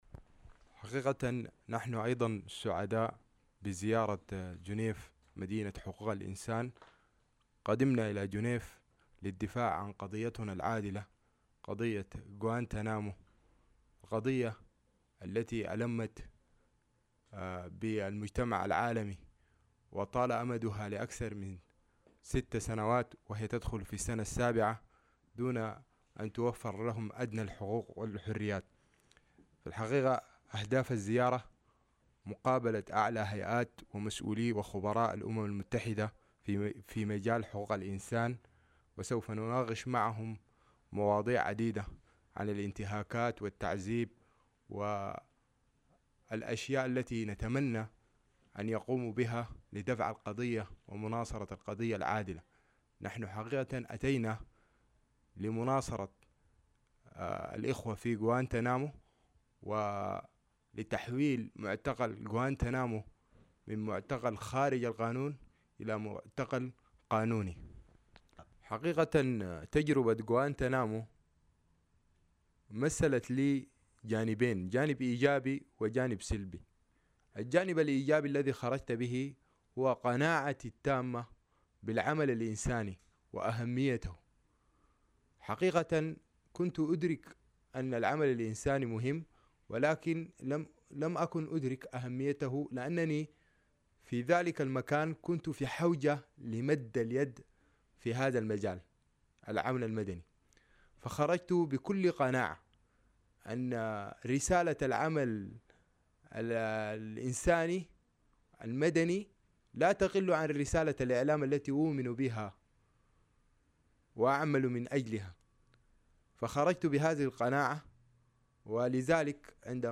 وفي حديث خص به سويس إنفو تحدث عن الدروس المستخلصة من فترة اعتقاله في غوانتانامو وشدد بالاخص على تجنده للدفاع عن حقوق الإنسان بشكل عام.